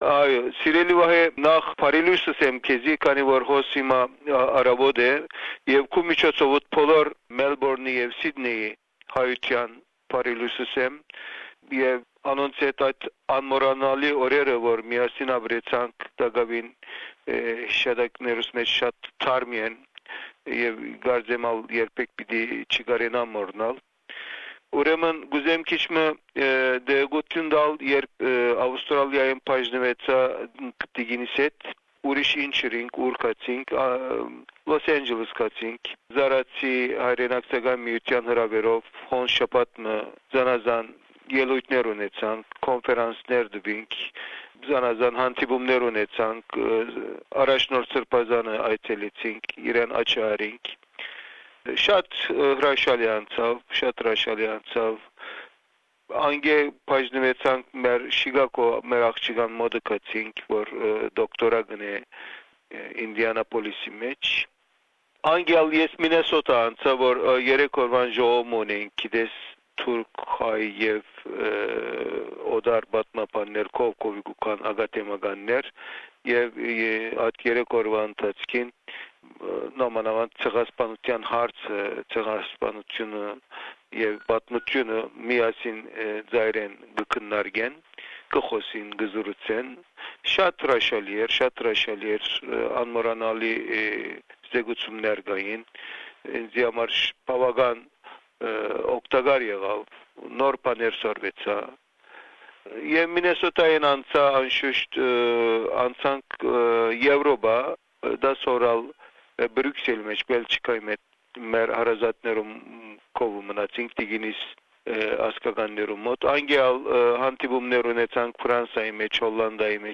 Hrant Dinks first report recorded in May 2003. The main topic of the interview is the official denial of the Armenian Genocide by the Turkish government.